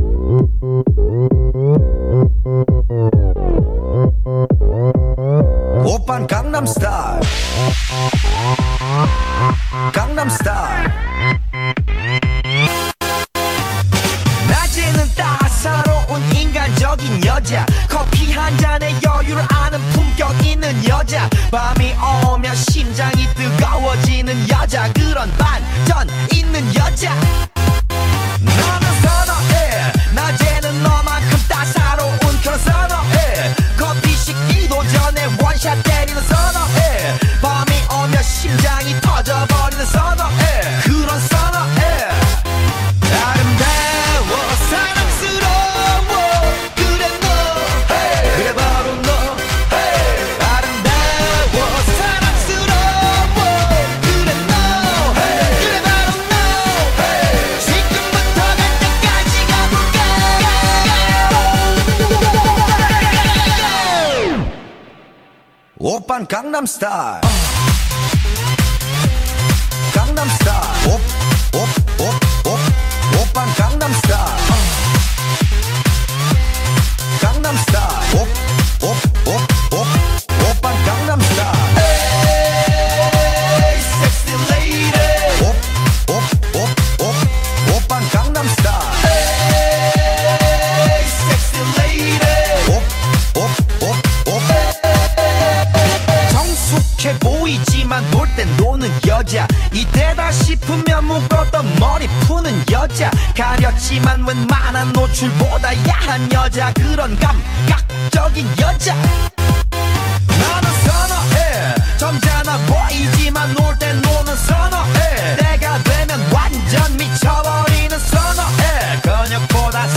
BPM66-264